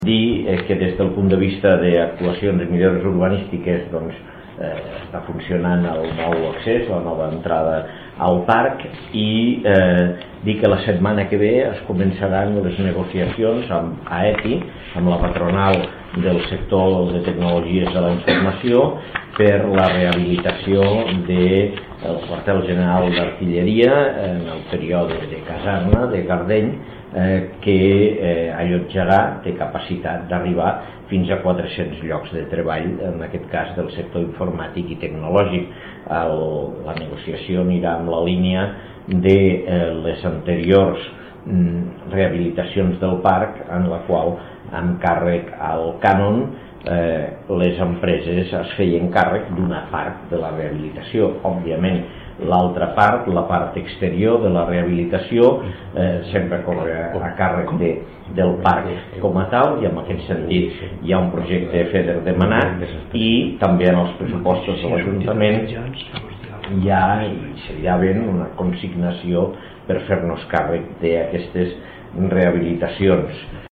tall-de-veu-de-lalcalde-de-lleida-angel-ros-sobre-el-consell-dadministracio-del-parc-cientific-de-lleida